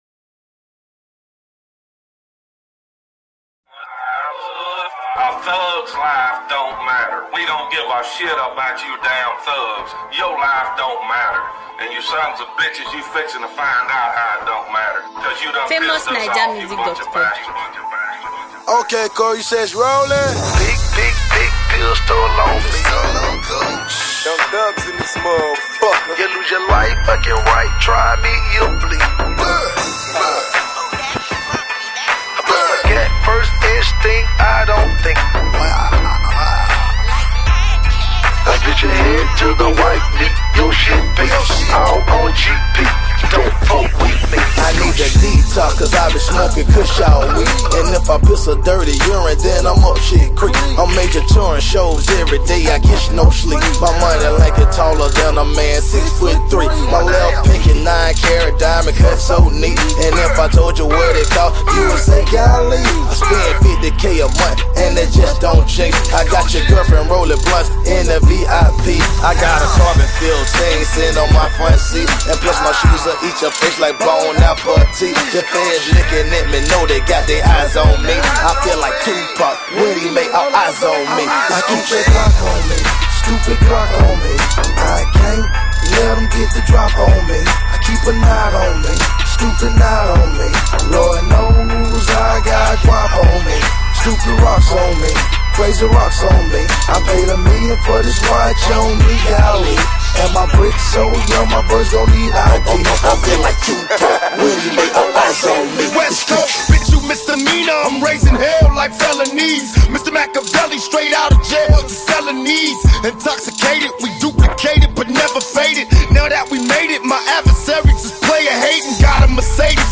R&B Trending